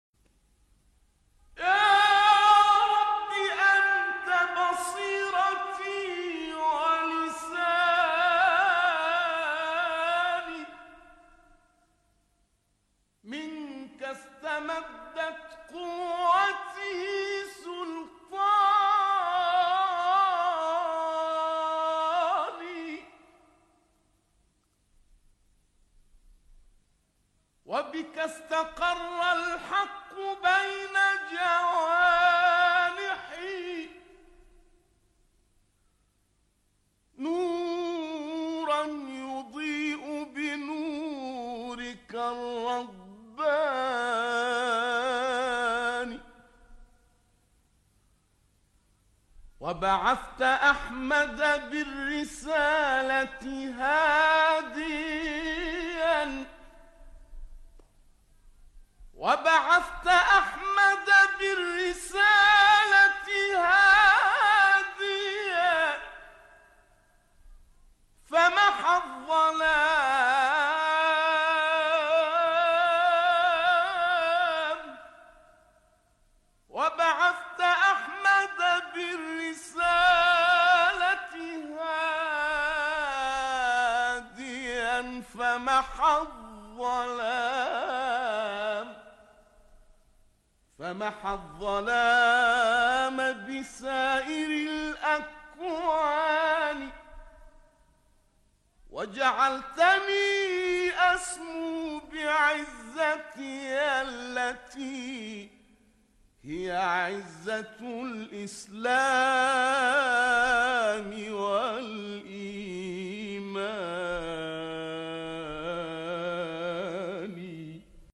به مناسبت ایام عید سعید فطر، عید بندگی و عبادت، مناجات زیبا و شنیدنی «یا رب، انت بصیرتی و لسانی» با صدای سید محمد نقشبندی، مبتهل بنام مصری را می‌شنوید.
برچسب ها: سید محمد نقشبندی ، ابتهال شنیدنی ، ابتهال دینی ، عید سعید فطر